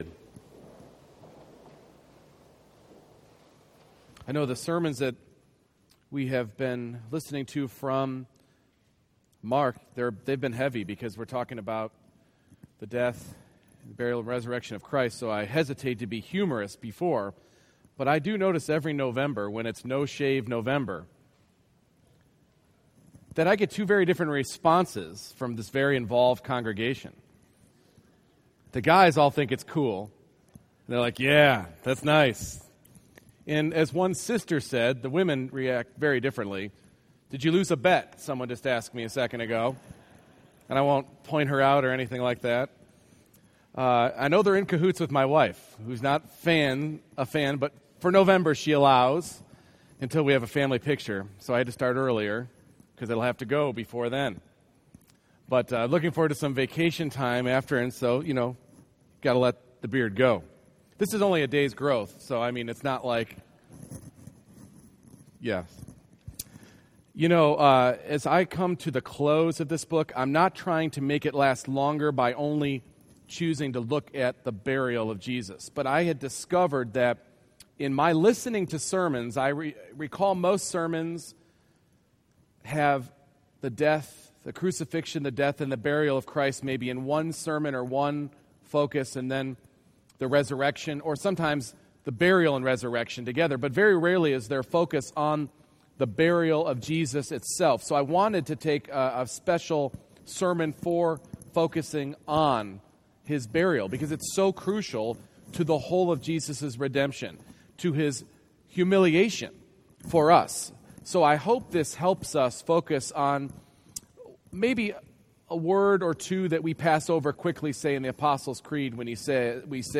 Mark 15:42-47 Service Type: Morning Worship Burial is the indisputable demonstration of death.